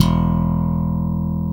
Index of /90_sSampleCDs/Roland L-CD701/BS _E.Bass v_s/BS _P.Bs_5S pop